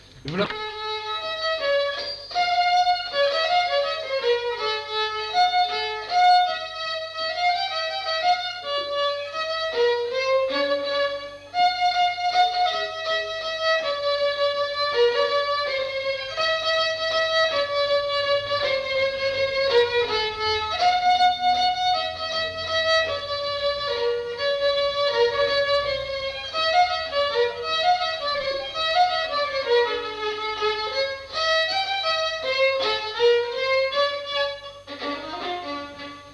Aire culturelle : Petites-Landes
Lieu : Roquefort
Genre : morceau instrumental
Instrument de musique : violon
Danse : java